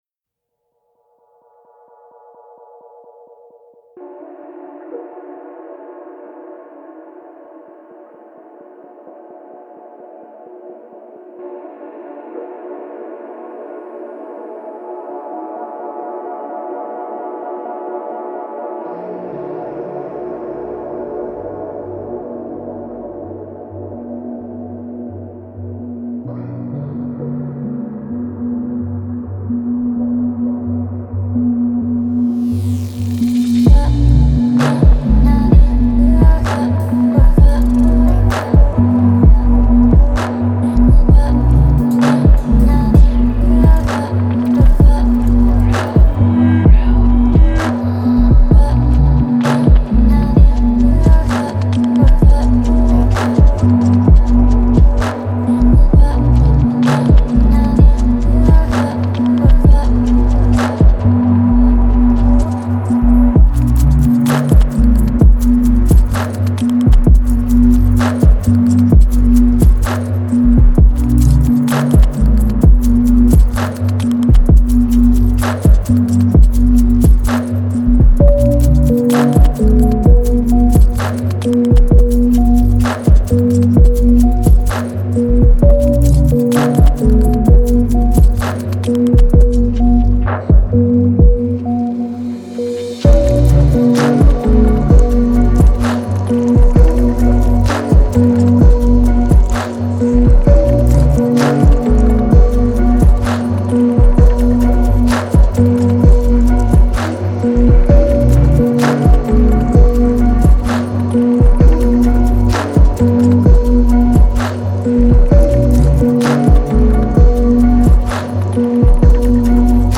это атмосферная композиция в жанре инди-поп